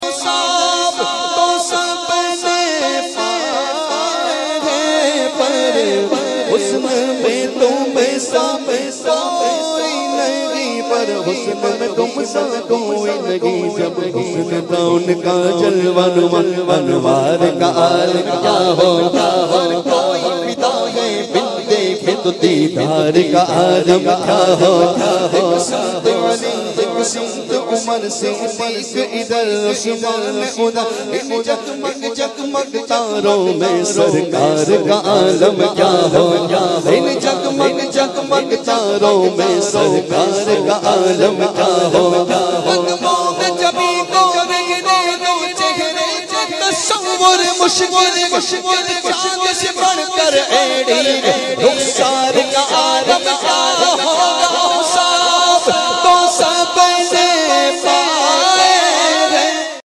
خوبصورت نعتیہ کلپ